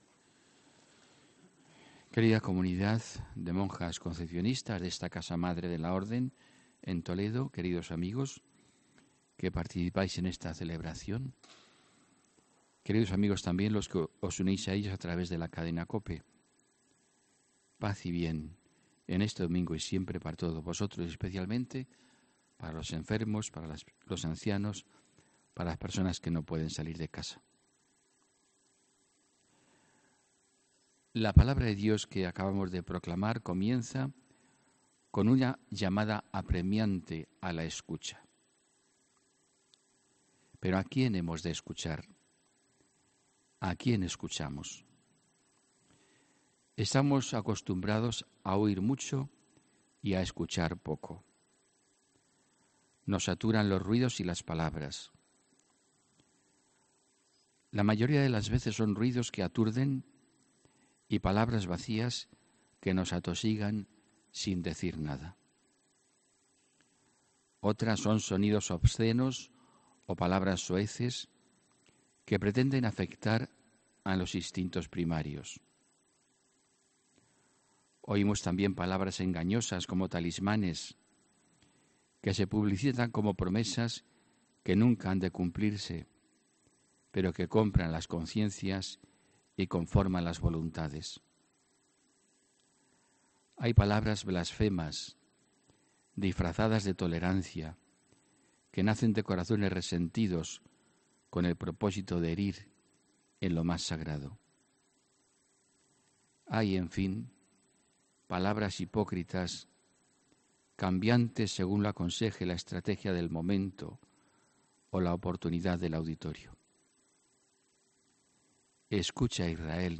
HOMILÍA 4 NOVIEMBRE 2018